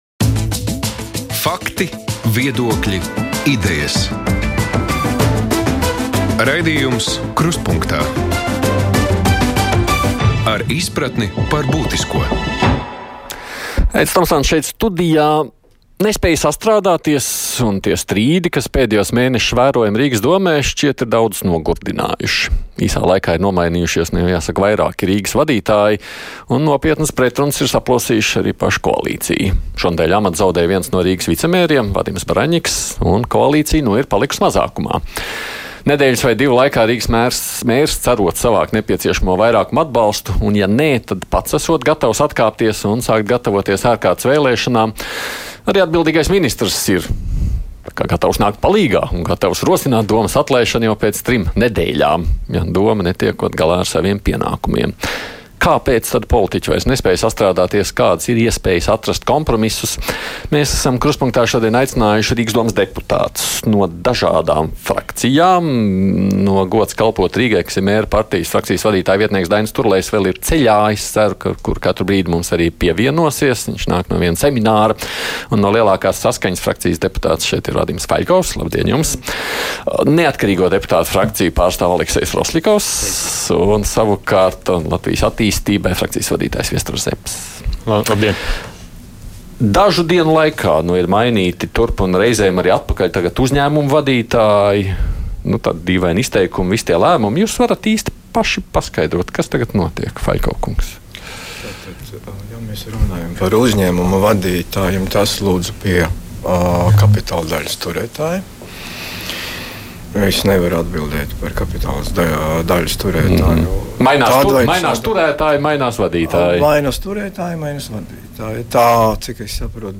Diskusija - Politiskās norises Rīgas Domē